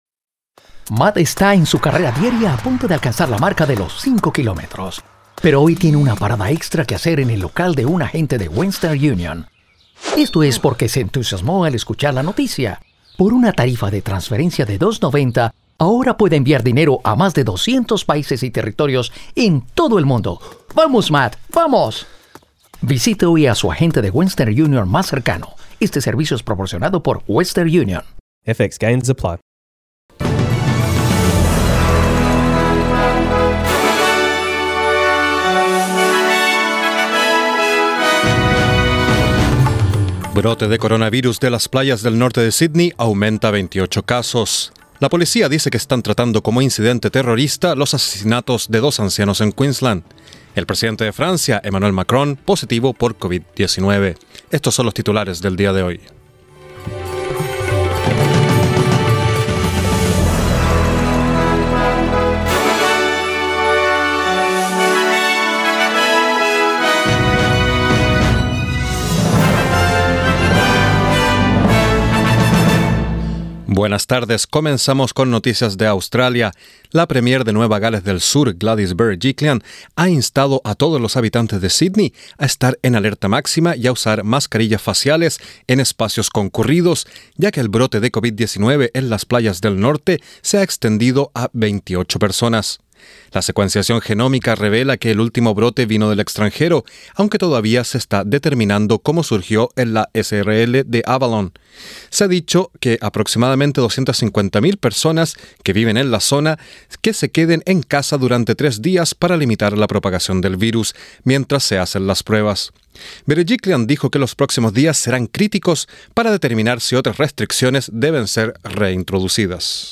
Noticias SBS Spanish | 18 diciembre 2020